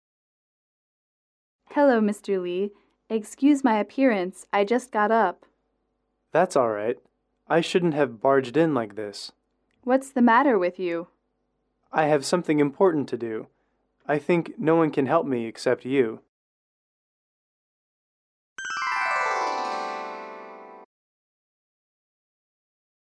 英语口语情景短对话14-2：不速之客(MP3)